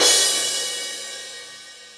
soft-hitfinish2.wav